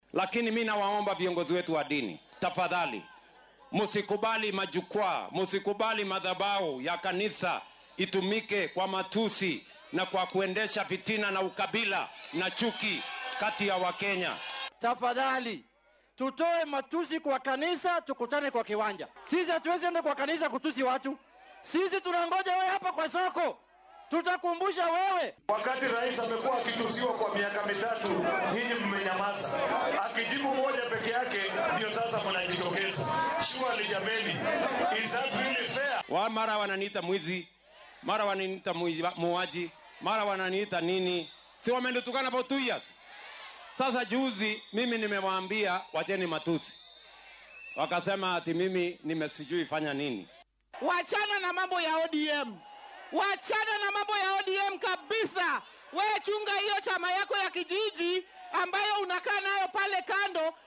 Madaxweyne William Ruto ayaa si kulul ugu jawaabay hoggaamiyeyaasha diinta ee dhaliilay hadalladiisa iyo dhaqankiisa, isaga oo uga digay in goobaha cibaadada loo adeegsado dagaal siyaasadeed. Waxaa uu sheegay in siyaasiyiinta qaarkood ay kaniisadaha u isticmaalaan faafinta aflagaado, nacayb iyo kala qaybin bulsho. Isagoo ka hadlayay deegaanka Yala ee ismaamulka Siaya, ayuu madaxweynaha ku eedeeyay mucaaradka in ay adeegsadaan goobaha diinta si ay u weeraraan hoggaanka, taasoo sii hurisay muran siyaasadeed oo maalmihii u dambeeyay sii xoogeysanayay.